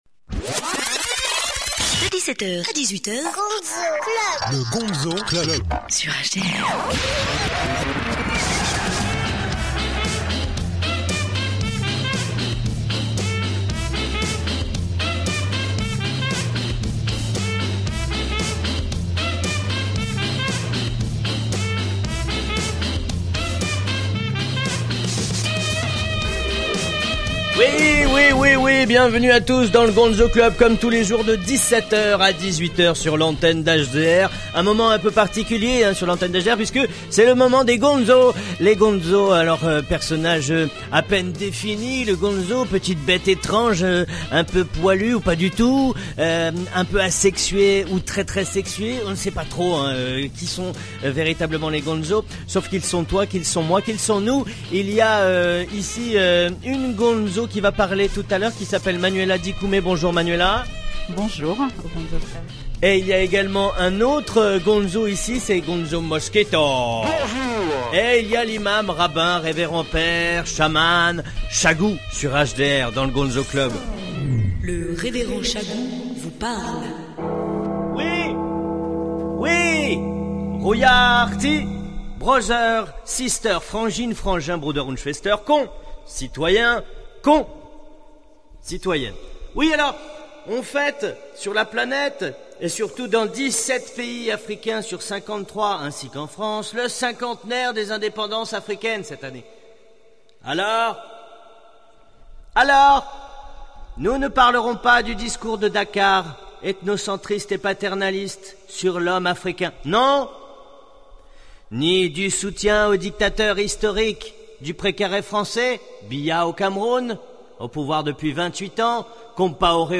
Le Gonzo Club est une émission diffusée tous les jours sur l'antenne d'HDR entre 17H et 18H. Une émission qui parle de musique et convie des invité(e)s.